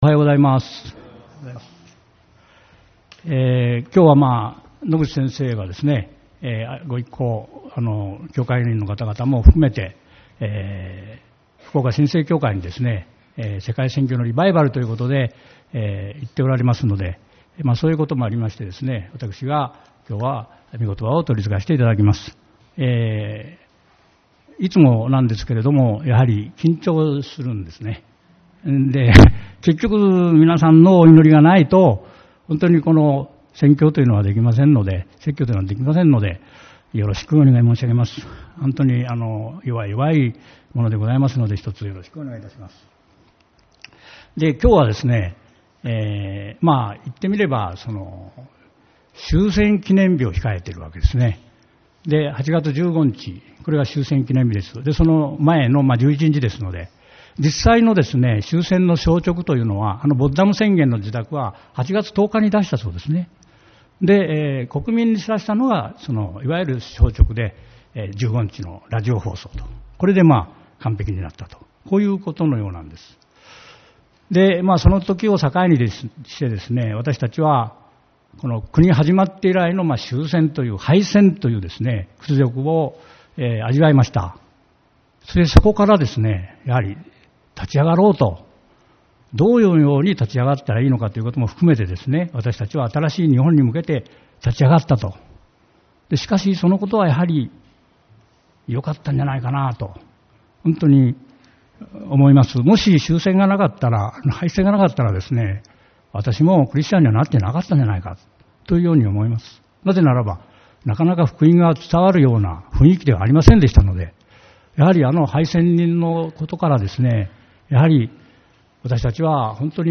8月11日主日礼拝 「律法を完成する信仰と祈り」